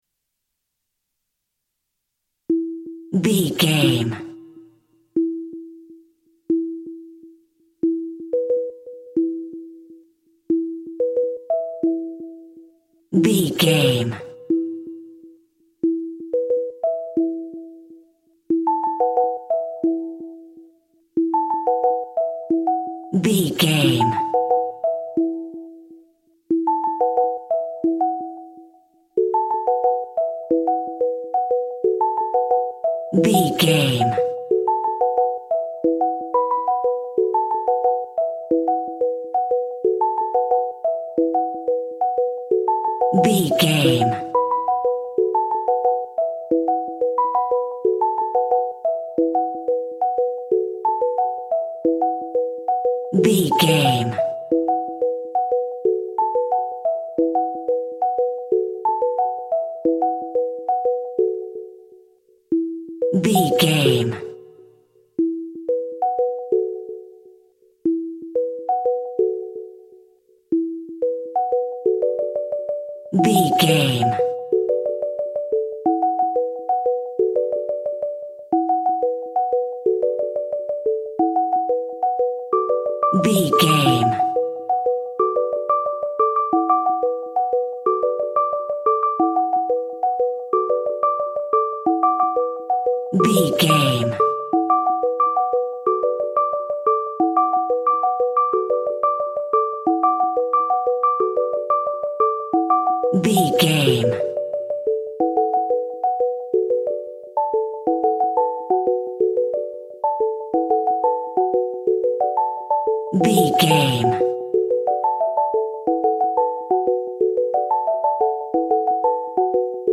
Melodic Electronic Music.
Aeolian/Minor
happy
uplifting
peaceful
electric piano
synthesiser
techno
trance
synth lead
synth bass
electronic drums
Synth Pads